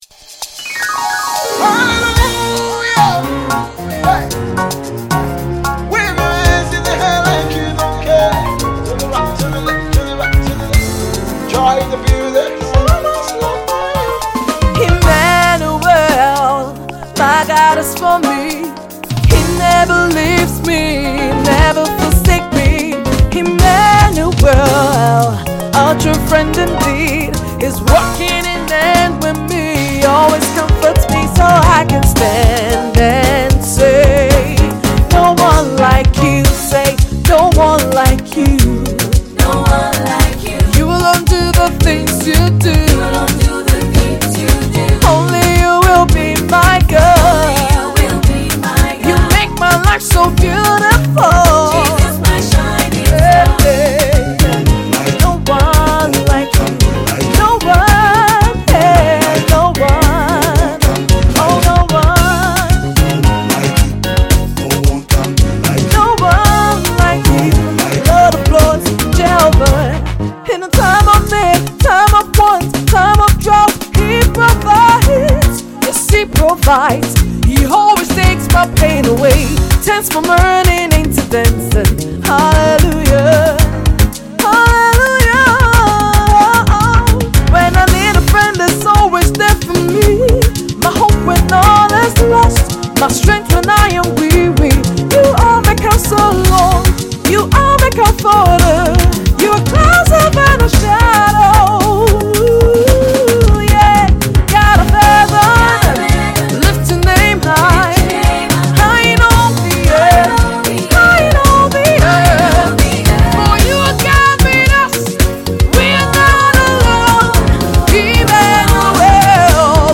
Uk based Nigerian Born gospel artiste